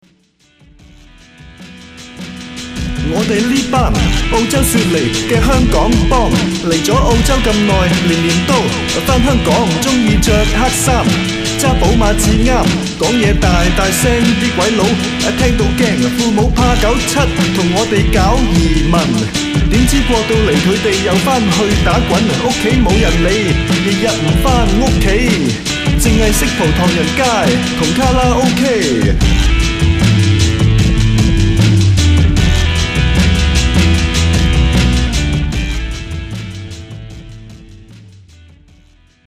hip hop style mixing with rock and folk